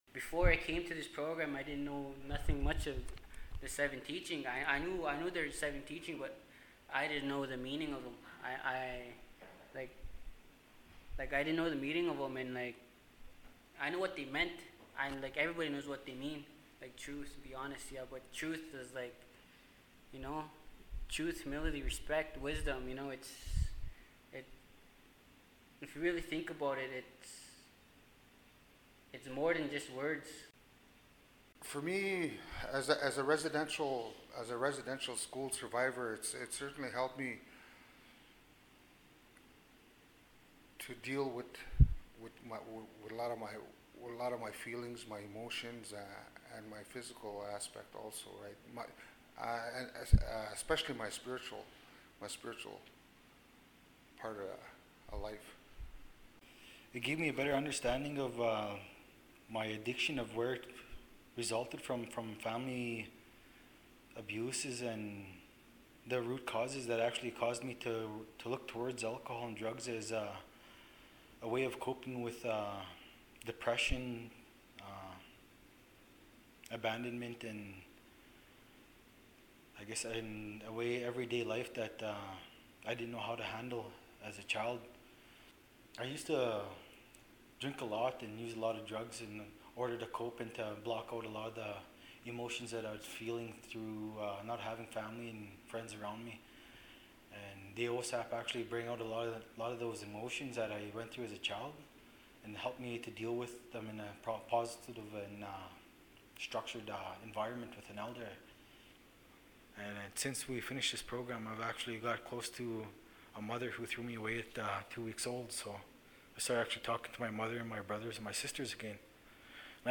Another perspective is the actual men who participated in the program, let us hear what they had to say.